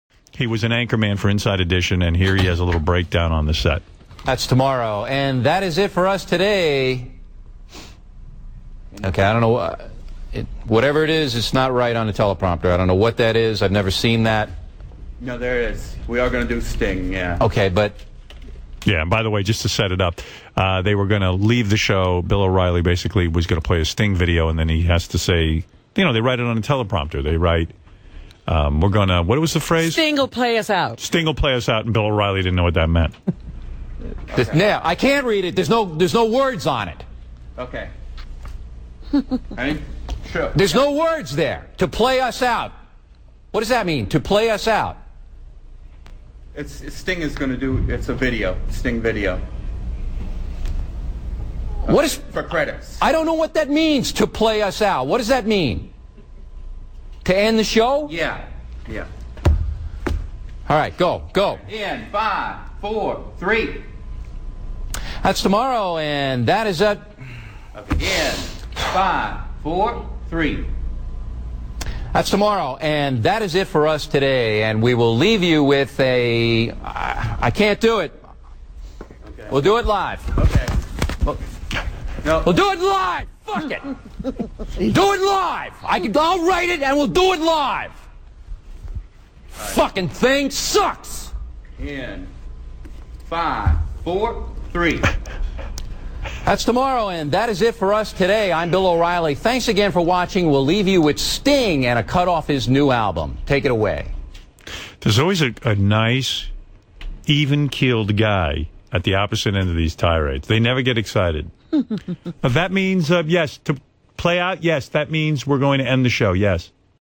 Something about Stern’s snarky laughter in the background makes the audio almost as entertaining as the video.